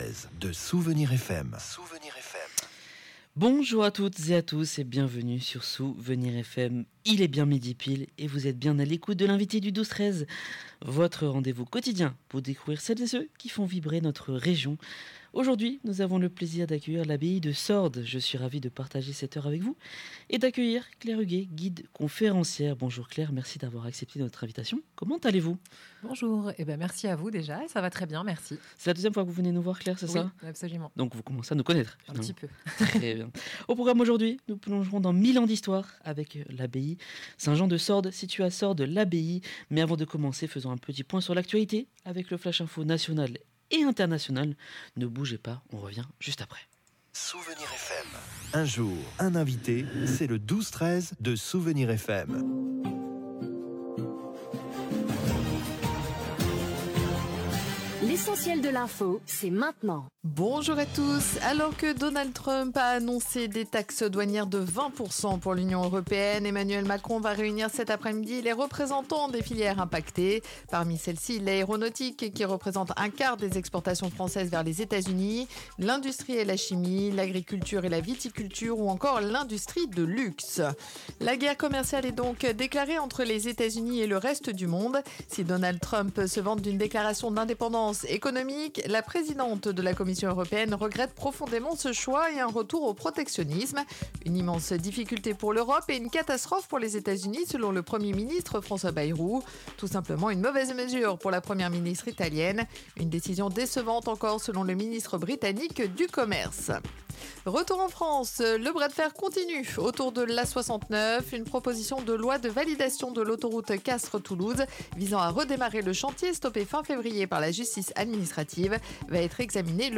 dans l’émission "L’invité du 12/13h"